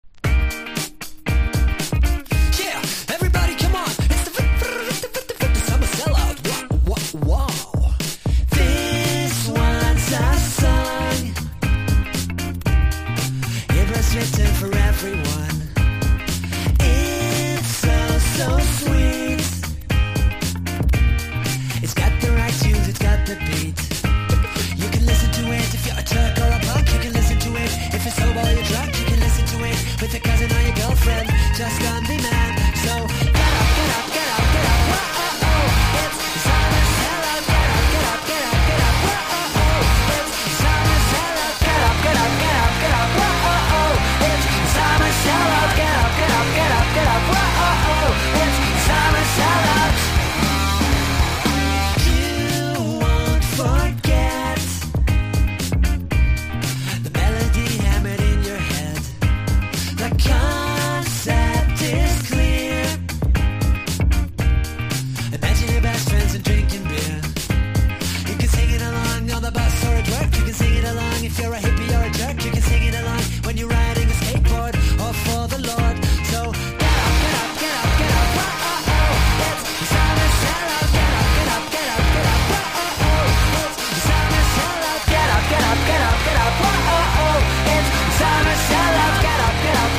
GUITAR POP# ロック名盤# CLUB HIT# MIXTURE / LOUD / HR# 90’s ROCK
軽めのイントロからサビでバースト & ラガ・パートまで飛び出すサマー・チューン！！